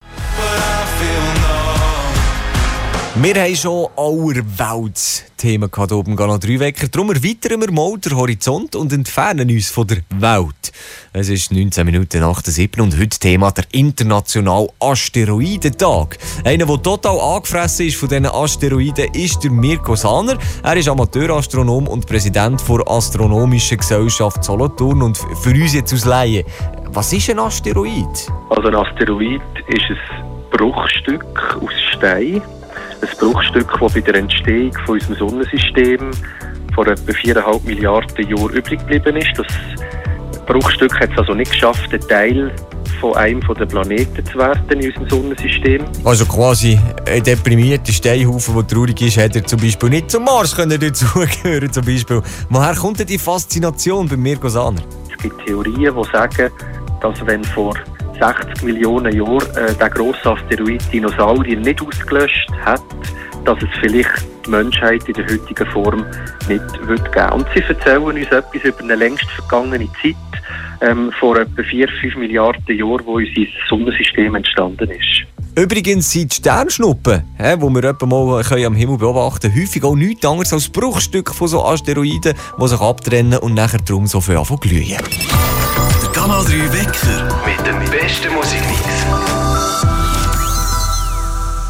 Interview zum Asteriodentag Ende Juni 2020 Canal 3 Teil #2